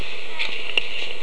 Electronic Voice Phenomena